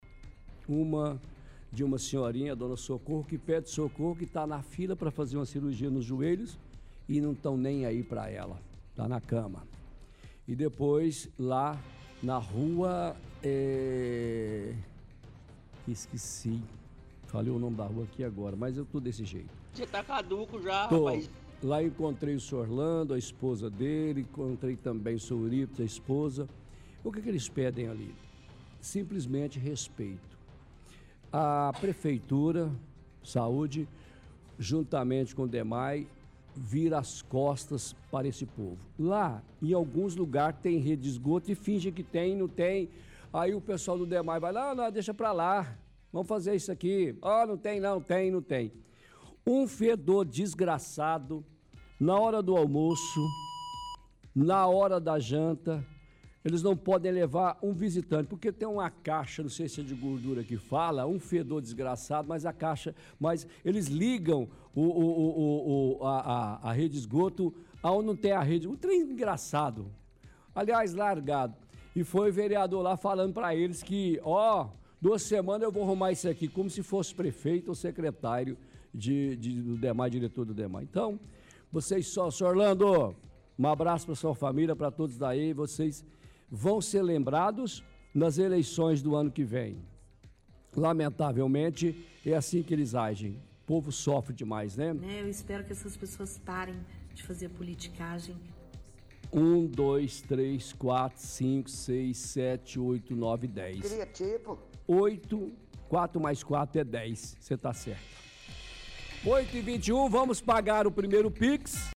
– Toca áudio da vereadora.